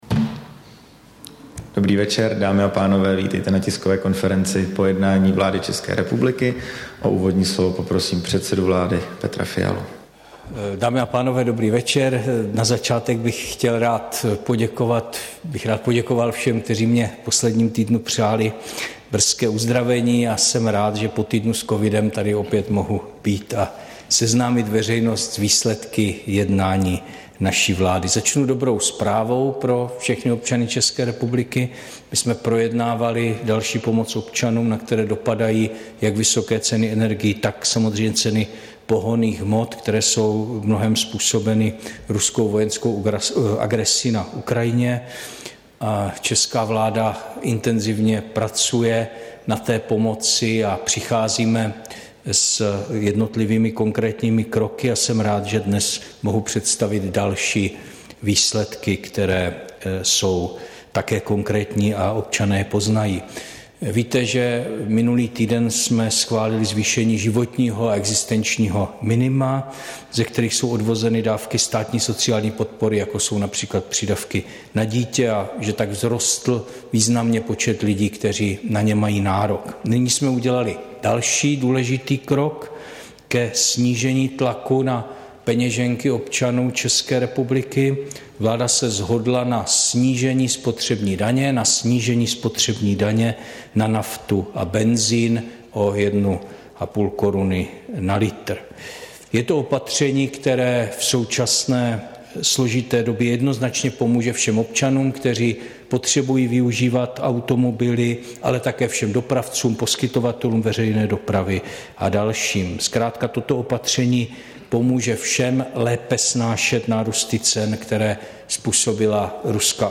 Tisková konference po jednání vlády, 6. dubna 2022
O úvodní slovo poprosím předsedu vlády Petra Fialu.